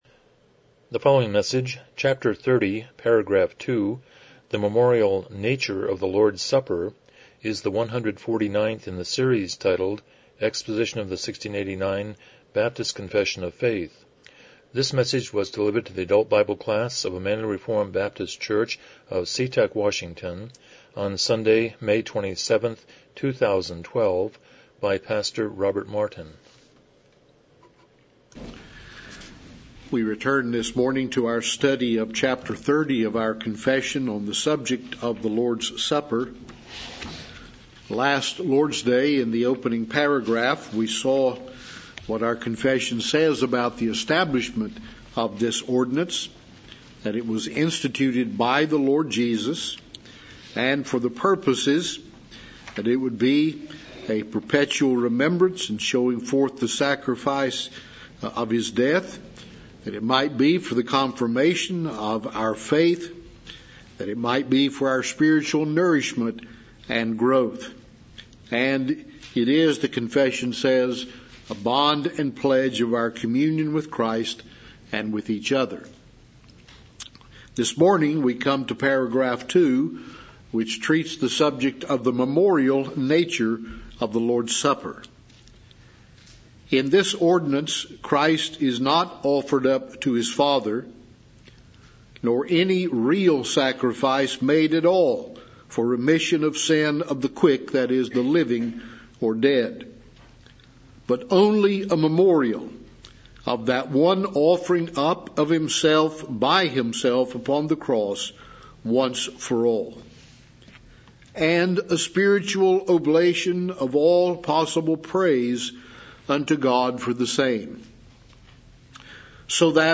Series: 1689 Confession of Faith Service Type: Sunday School « 2 The Preface